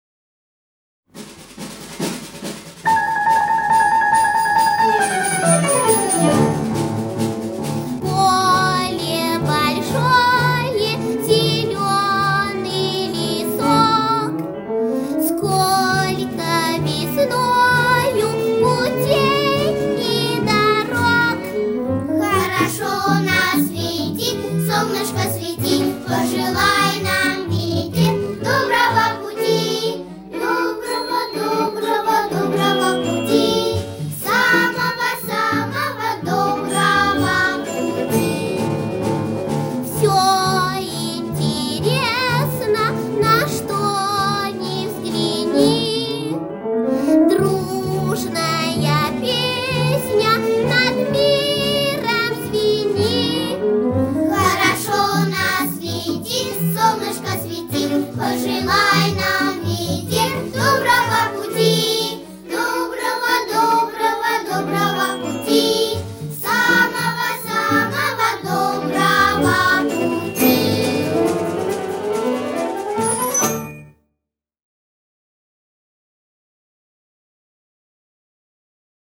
музыка детская